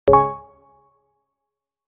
دانلود آهنگ کلیک 8 از افکت صوتی اشیاء
جلوه های صوتی
دانلود صدای کلیک 8 از ساعد نیوز با لینک مستقیم و کیفیت بالا